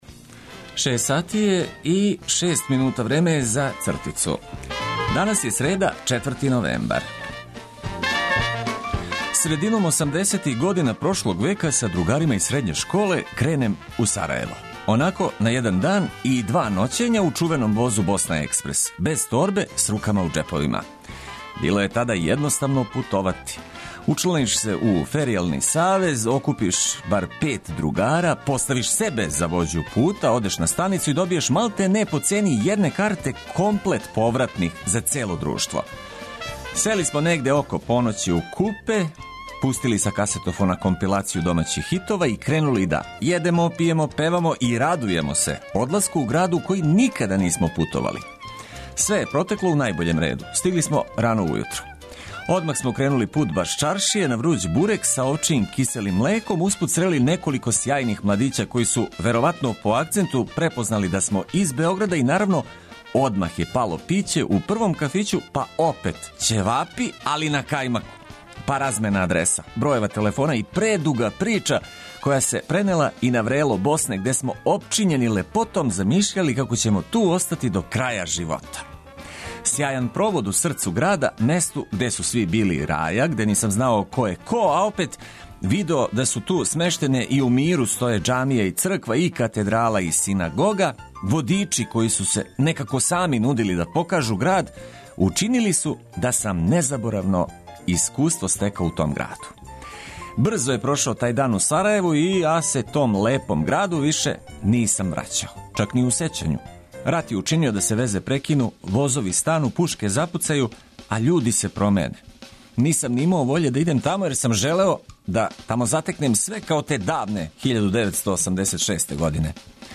Нека дан почне уз добру музику коју ћемо прошарати информацијама од којих ћете имати користи.